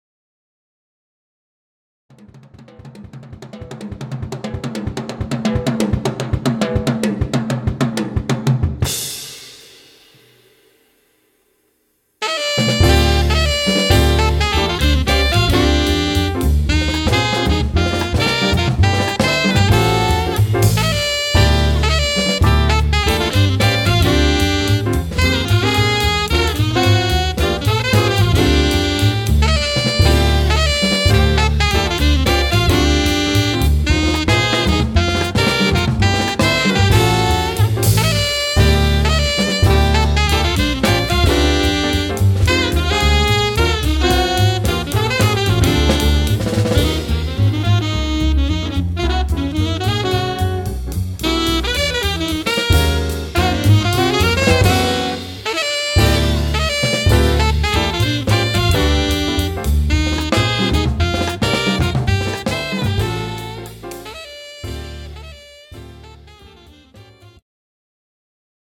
The Best In British Jazz
Recorded at Clowns Pocket Studio, London 2014